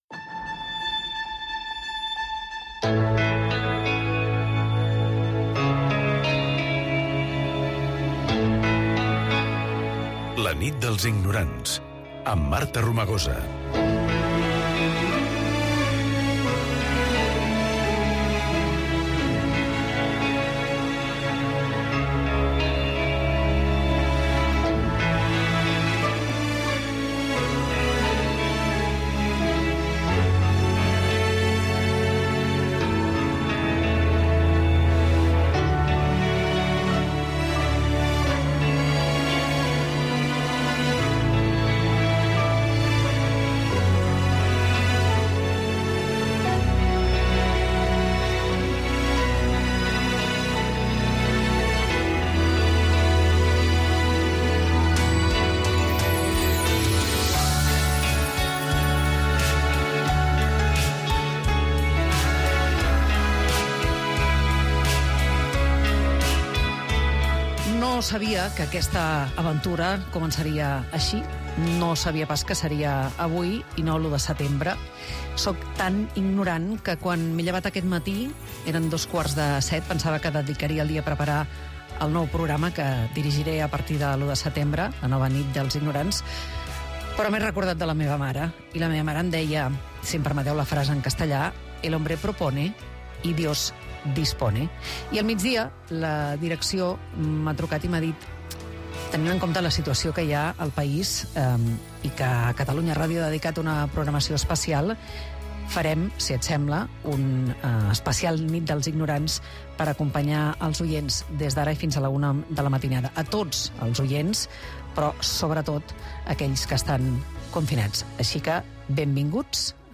8b76aa767ce6902b8ec9f596d775877d1fbcceb5.mp3 Títol Catalunya Ràdio Emissora Catalunya Ràdio Cadena Catalunya Ràdio Titularitat Pública nacional Nom programa La nit dels ignorants Descripció Espai especial.
Informació de la situació dels incendis de Paüls, Xerta i Sant Pere Sallavinera. Invitació a la participació, trucada d'una oïdora des de Xerta